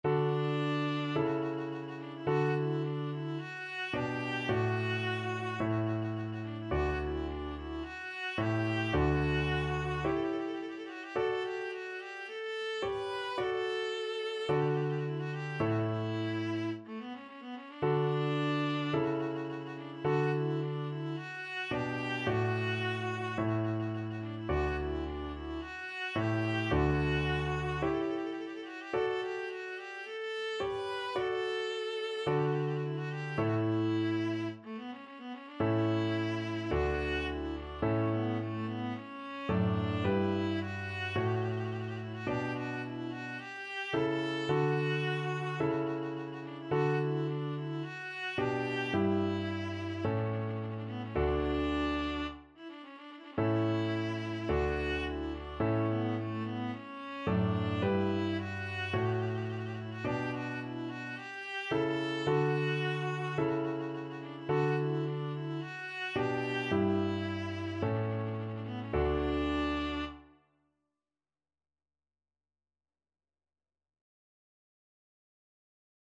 4/4 (View more 4/4 Music)
= 54 Slow
Classical (View more Classical Viola Music)